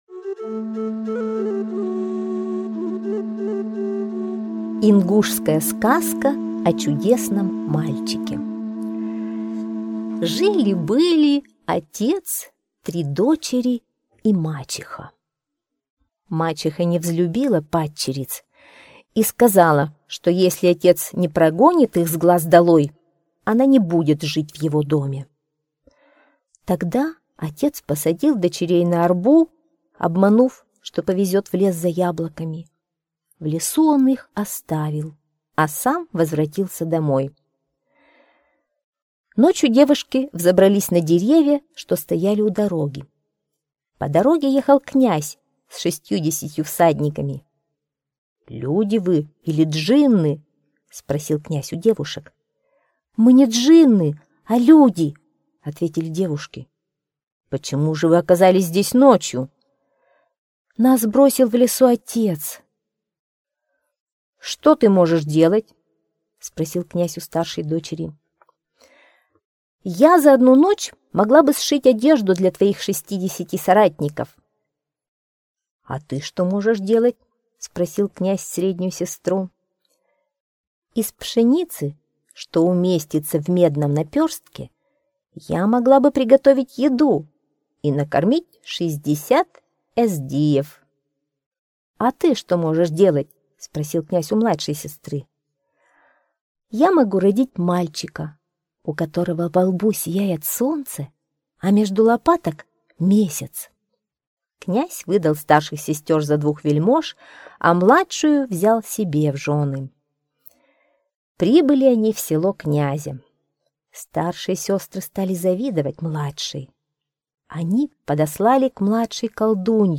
Сказка о чудесном мальчике - ингушская аудиосказка - слушать